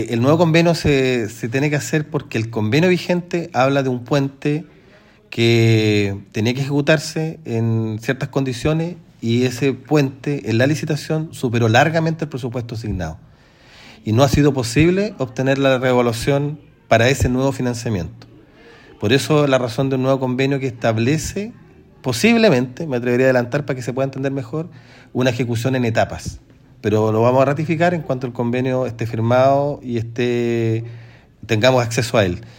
Así lo reconoció el delegado presidencial, Jorge Alvial, sobre esta iniciativa, cuyo mandante es el Ministerio de Vivienda y Urbanismo y tiene al Ministerio de Obras Públicas como acompañante técnico.